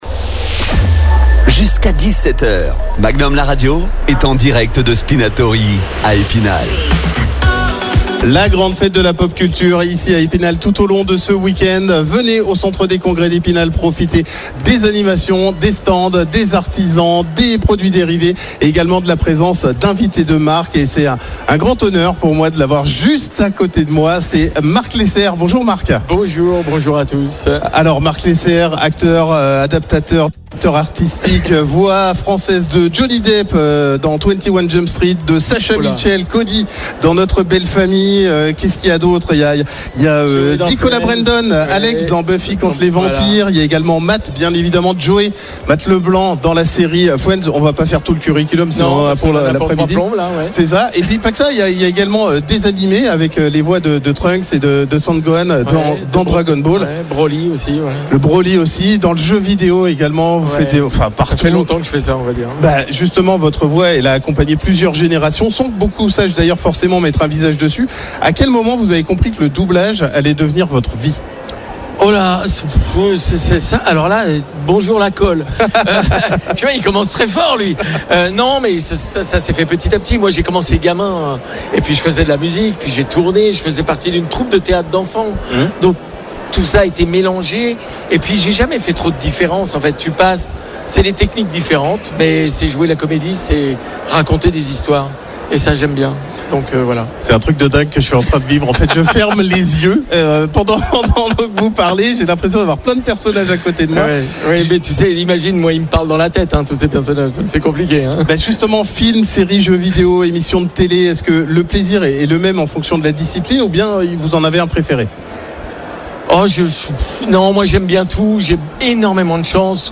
comédien de doublage